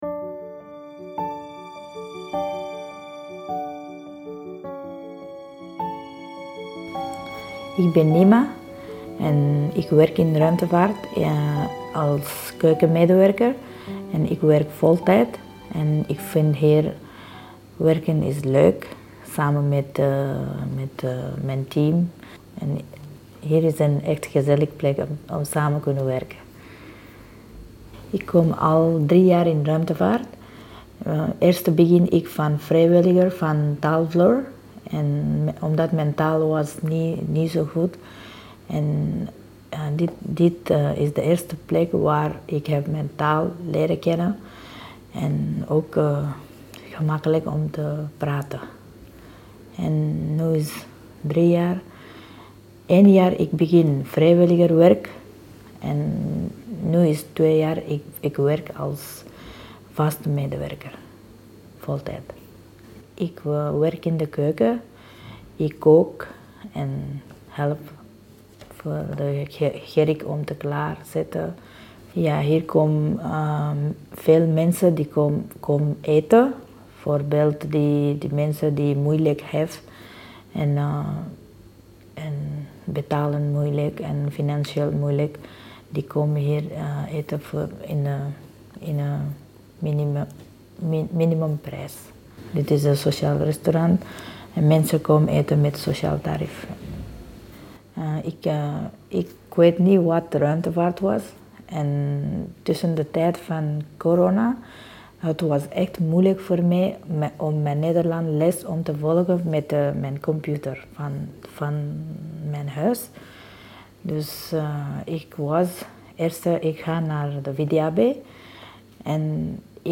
Elk van hen nam ons ook mee naar een plek in de buurt waar zij zich goed voelen. Daar maakten we telkens een audioverhaal.